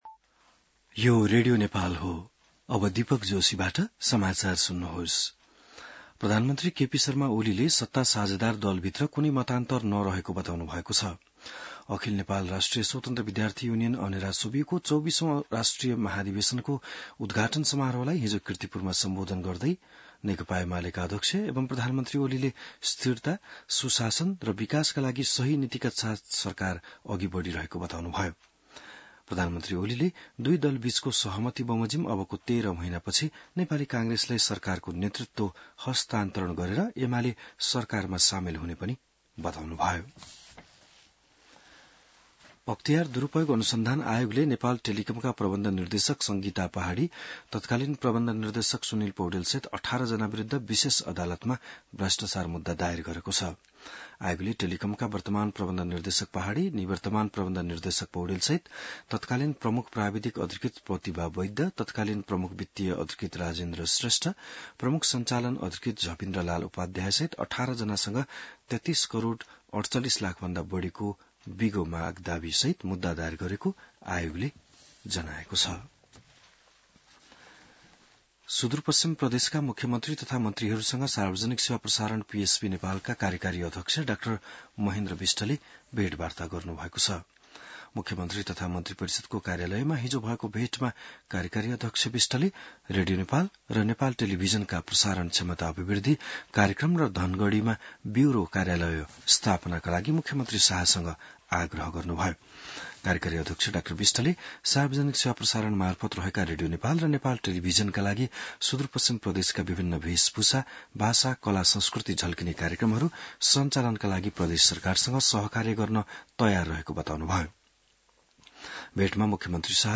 बिहान १० बजेको नेपाली समाचार : २६ जेठ , २०८२